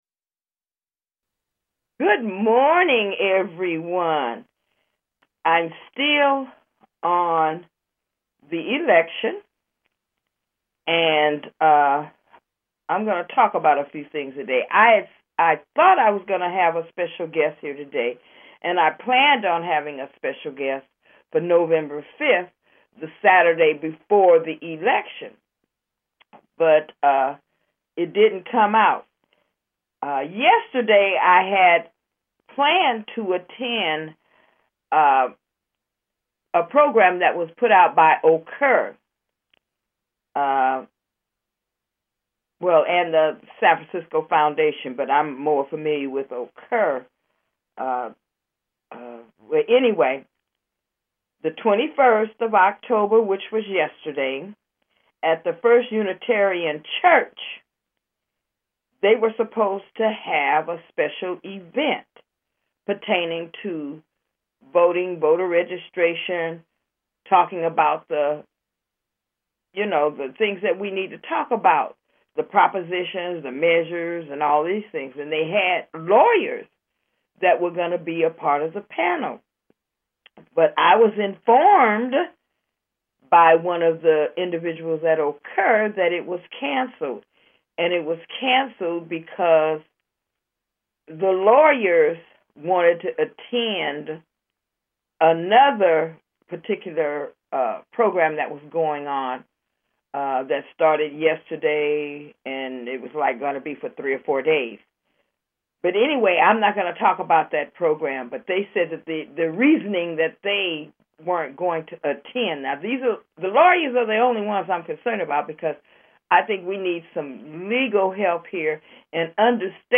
SBFM Radio